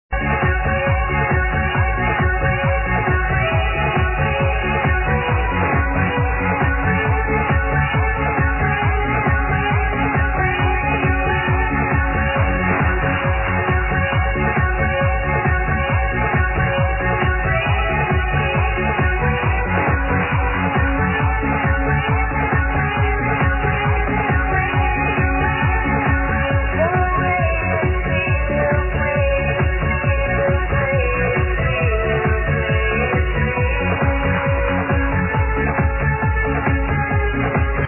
My new oldskool TRANCE mix!
excellent tune, great vocals